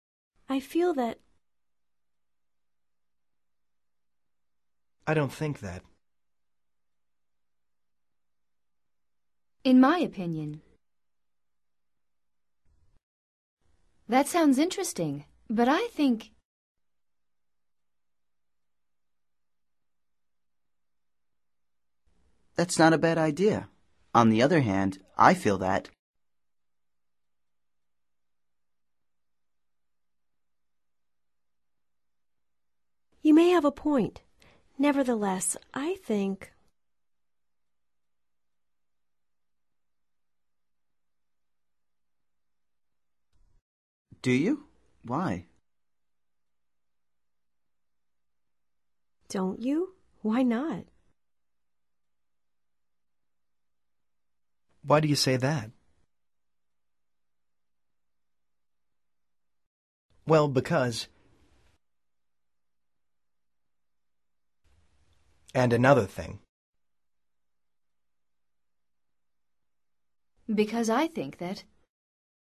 This exercise practices several useful conversational structures about giving opinions. Listen and repeat after each expression trying to imitate the speakers.